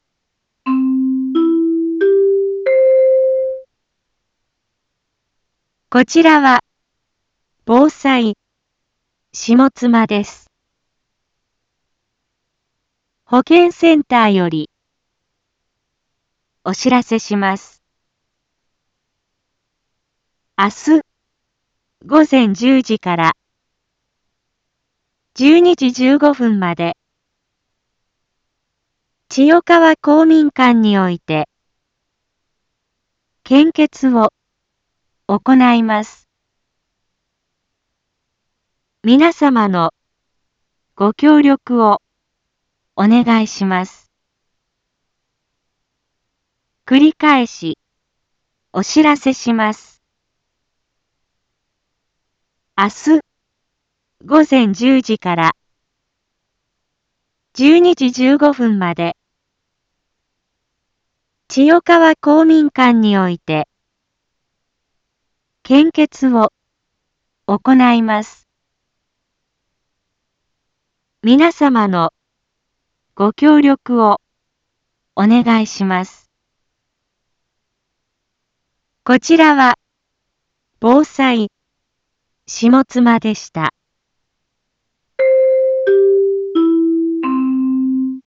一般放送情報
Back Home 一般放送情報 音声放送 再生 一般放送情報 登録日時：2022-03-24 18:31:32 タイトル：献血のお知らせ（前日報） インフォメーション：こちらは防災下妻です。